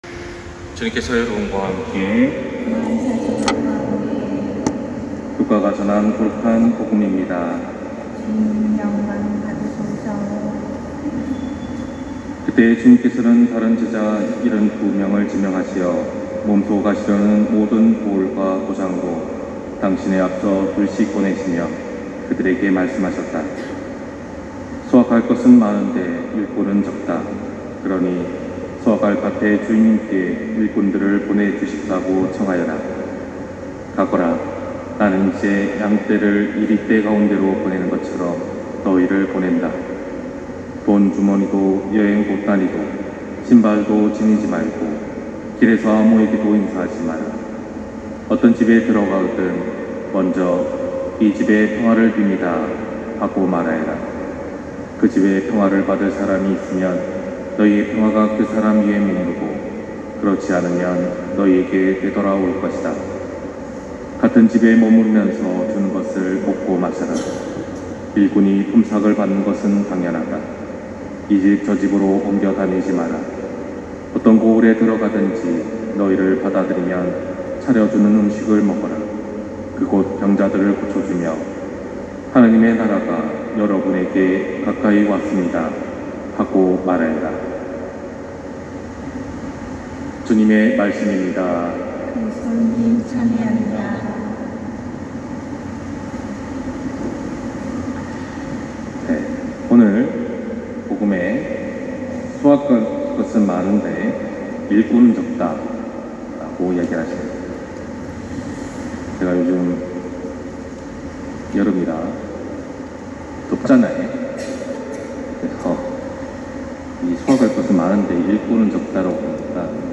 250704신부님 강론말씀